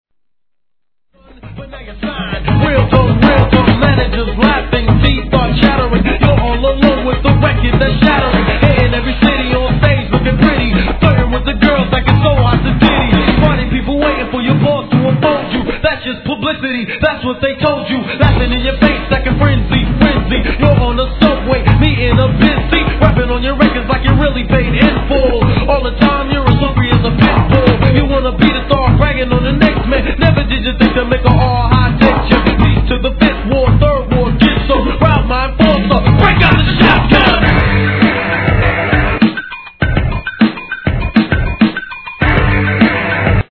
HIP HOP/R&B
オールドスクール！！